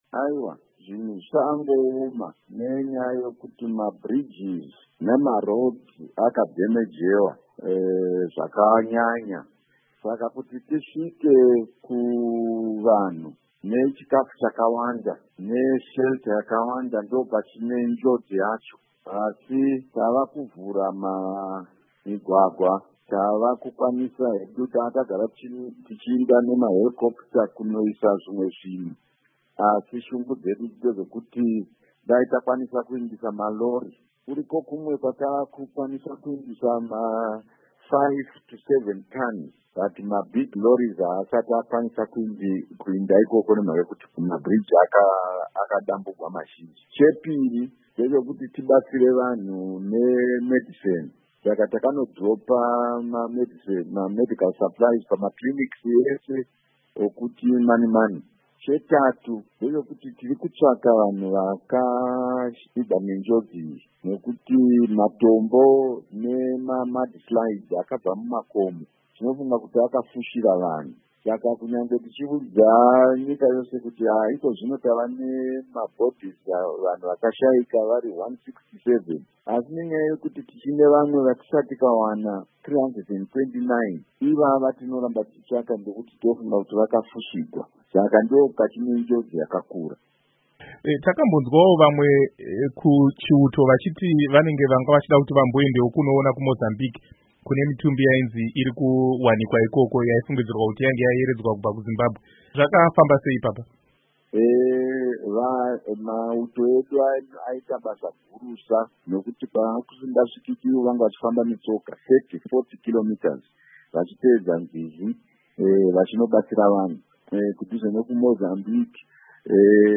Hurukuro naVaJuly Moyo